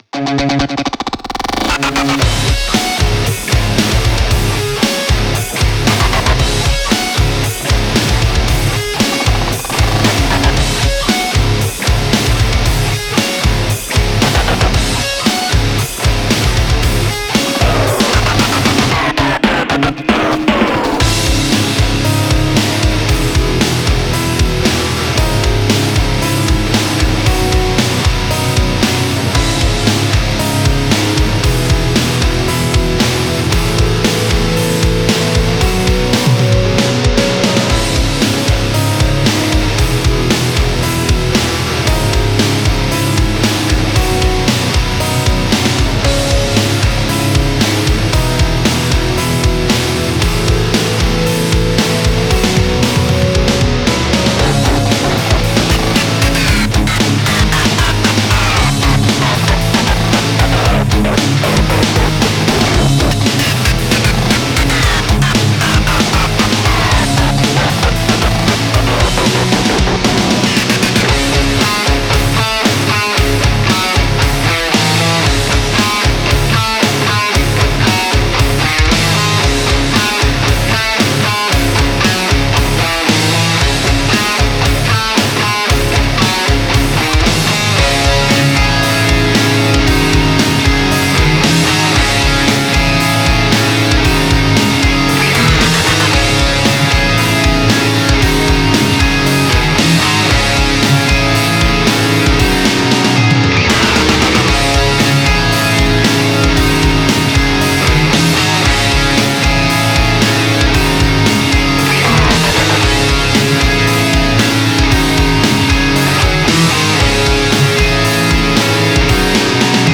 ヘヴィなリフにピアノとスクラ